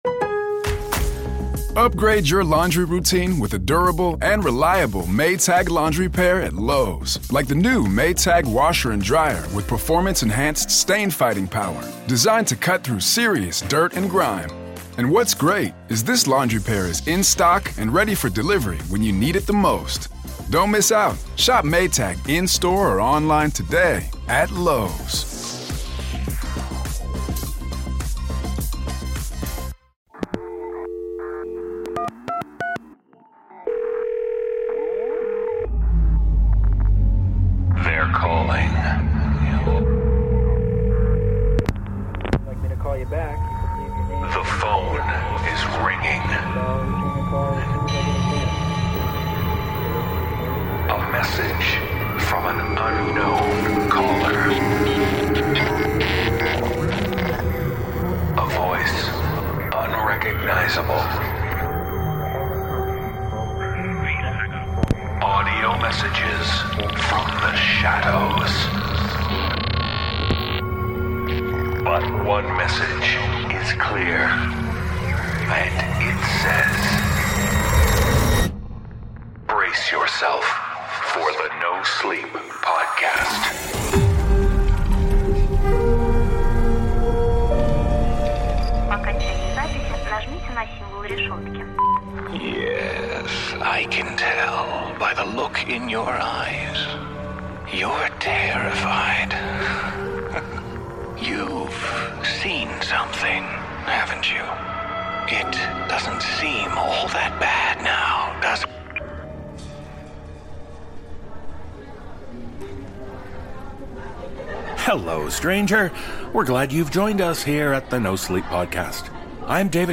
The voices are calling with tales of strange strangers.